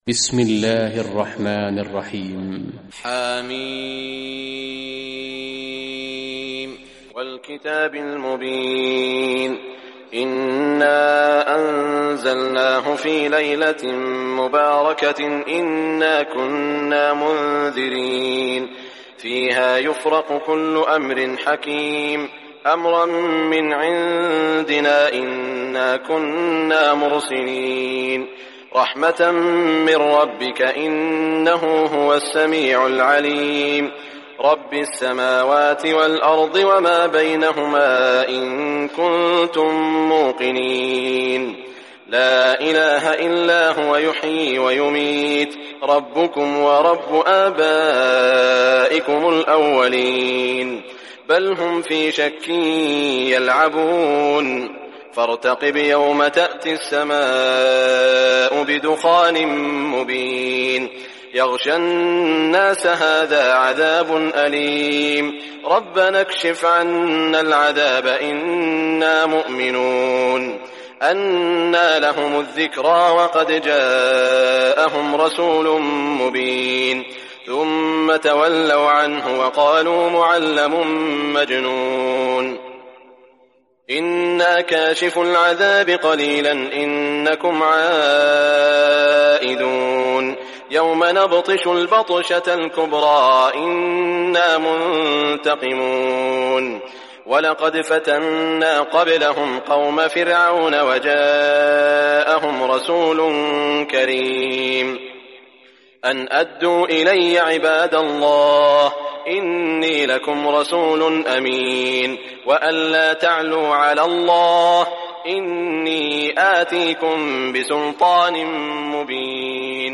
Surah Ad-Dukhan Recitation by Sheikh Shuraim
Surah Ad-Dukhan, listen or play online mp3 tilawat / recitation in Arabic in the beautiful voice of Sheikh Saud al Shuraim.